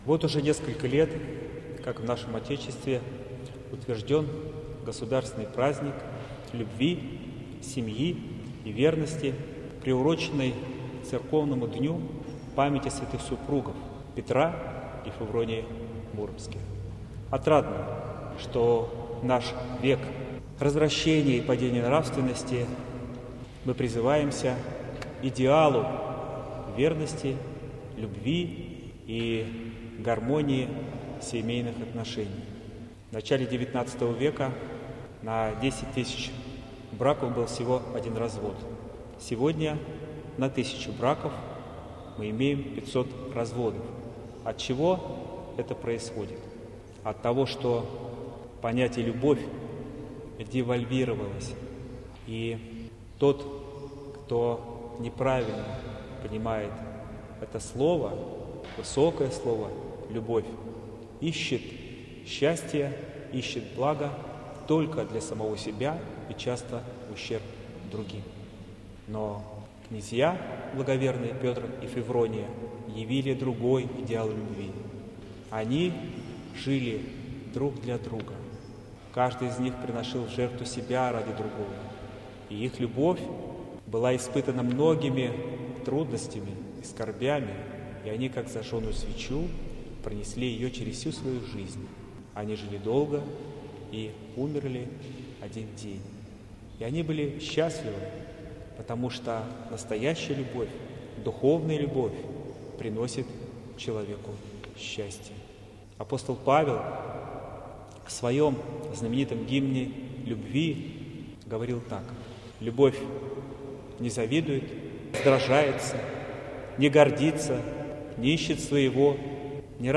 Поздравление главы Выксунской епархии с днем памяти преподобных муромских князей Петра и Февронии.